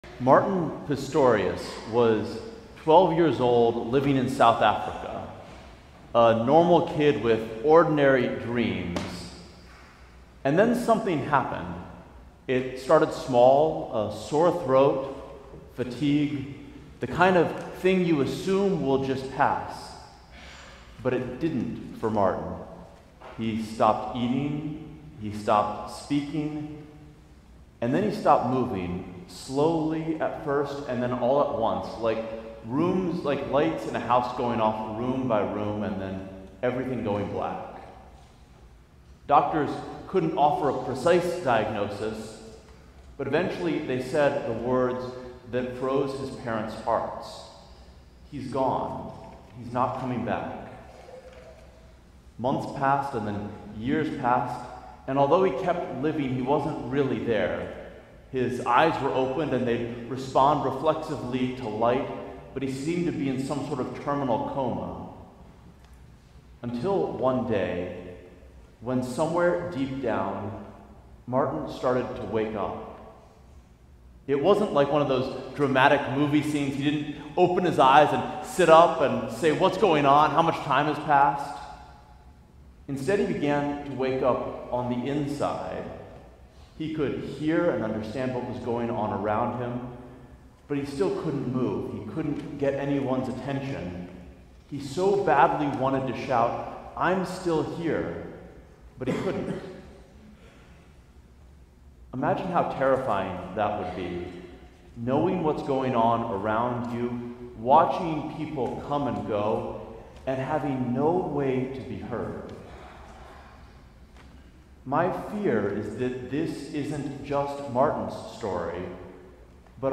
Sermon: When God comes close - St. John's Cathedral